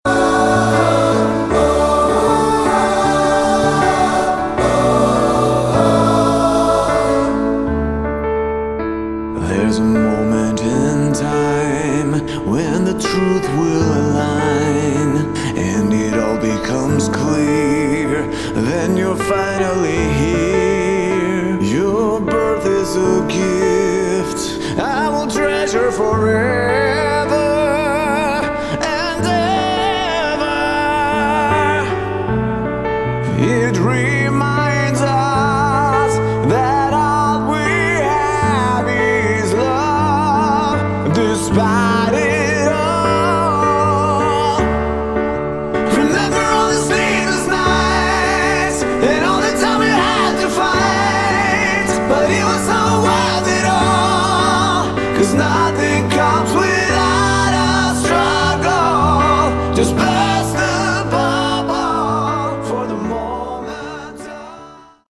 piano version